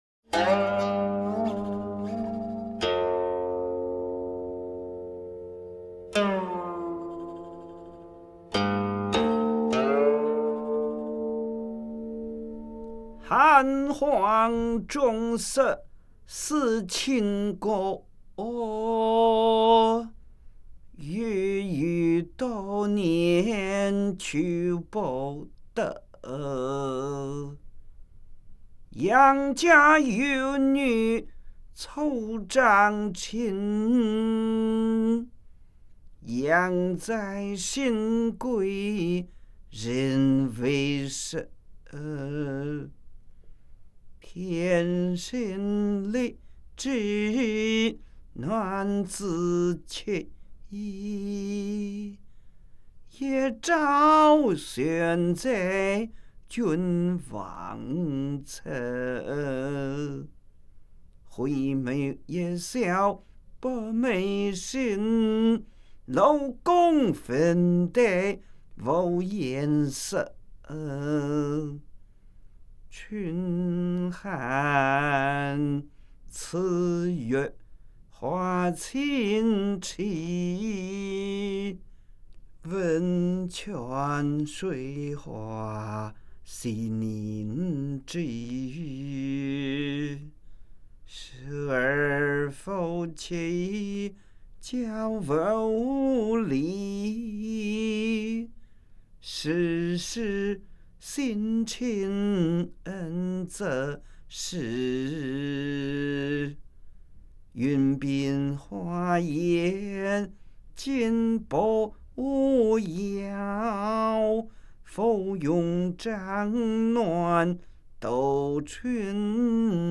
吟誦